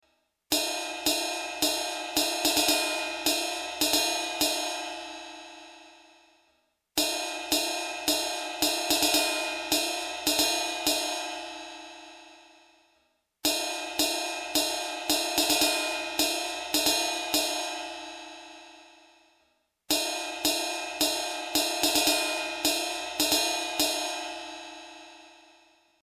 The sound samples are once again taken from the trusty Roland R-8.
Ride 2 (in between center and edge): 303Hz
Played 4×11 (rhythmic riff)
Notice how the closer we get to the center of the ride, the denser and lower the frequency!
(Higher and lower octaves and harmonics present)
fk4_Ride_2_Further_In_300Hz.mp3